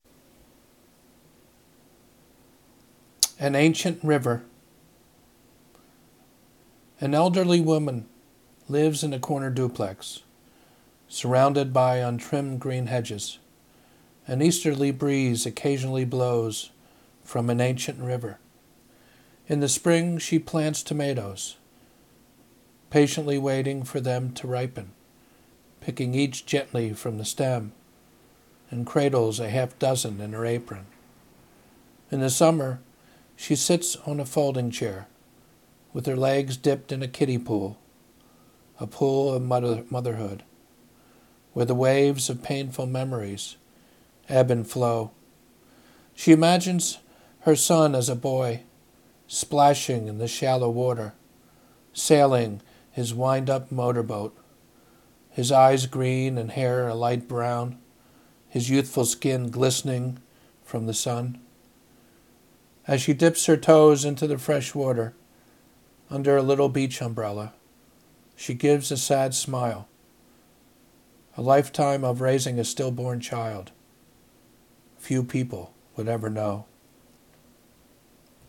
audio poem Poetry poetry anthology